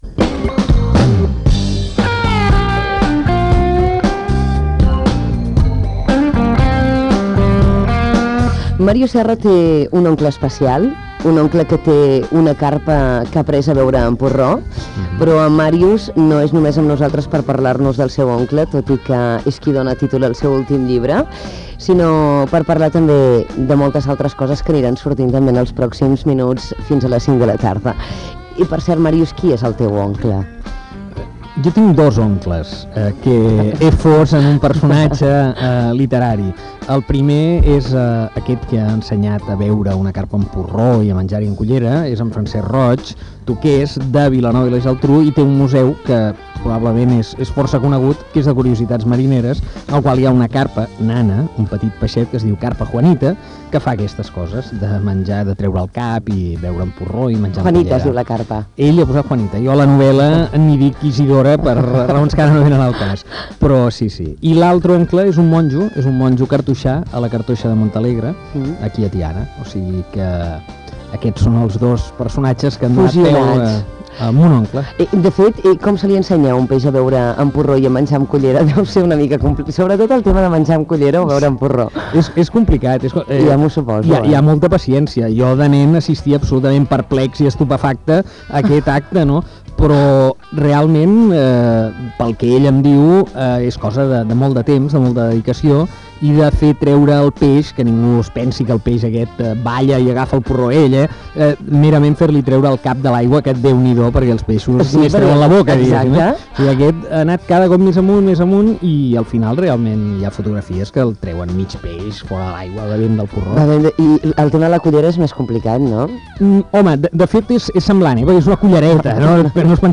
Entrevista a Màrius Serra que presenta la novel·la "Mon oncle"